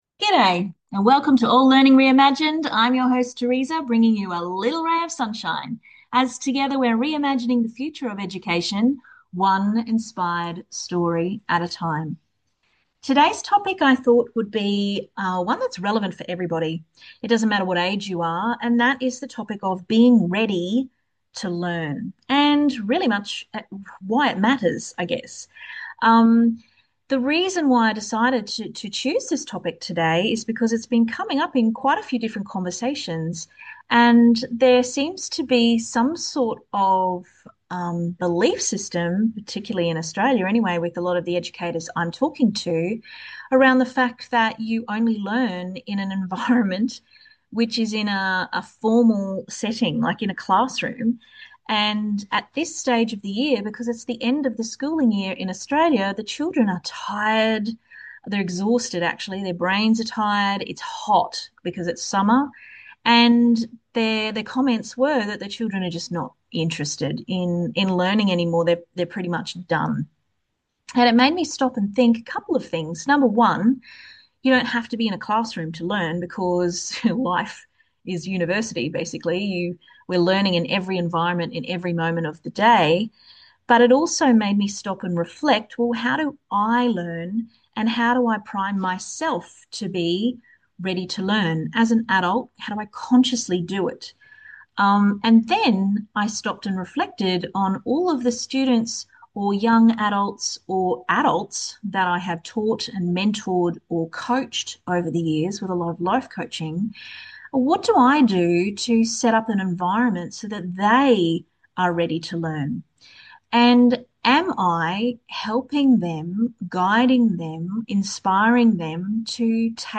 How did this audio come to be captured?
Through heartfelt conversations, reflections and skill-sharing from around the world, we spotlight real-life stories and ideas that break free from rigid educational models.